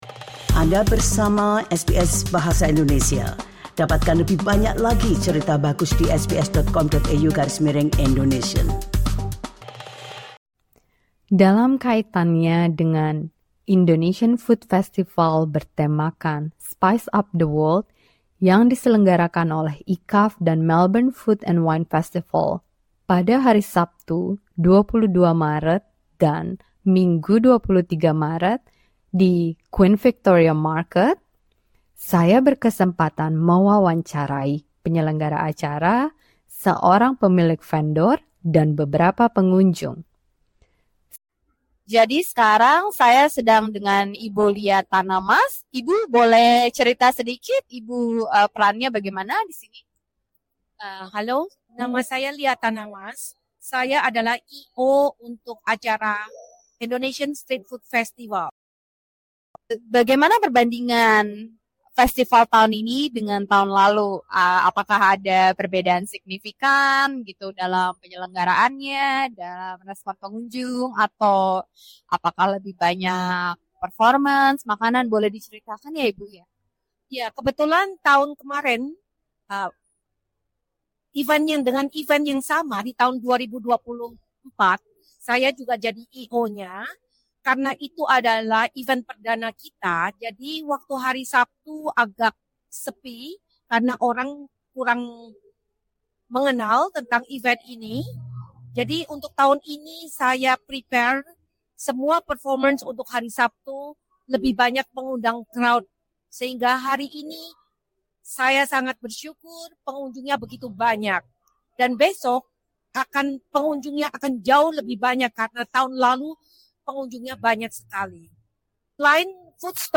SBS Indonesian mewawancarai penyelenggara acara, salah satu peserta, serta beberapa diaspora Indonesia yang jadi pelanggan acara ini.